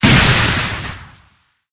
ZombieSkill_SFX
sfx_skill 03_3.wav